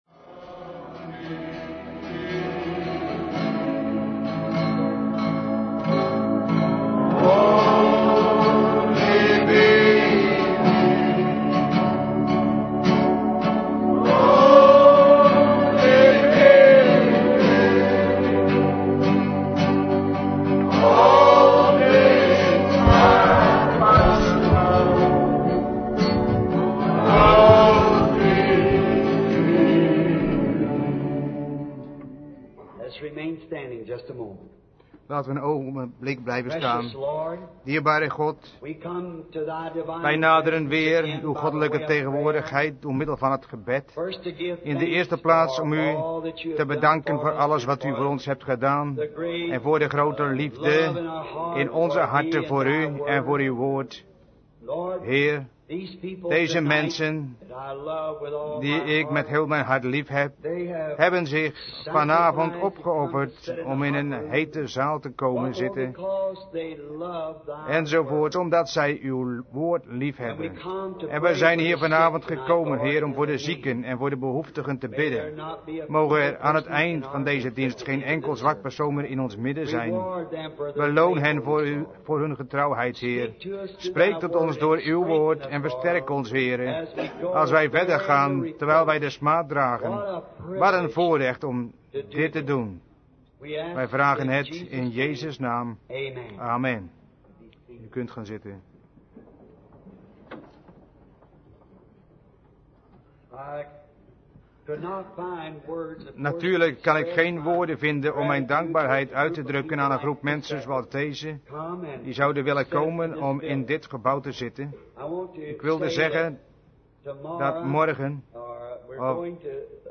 Vertaalde prediking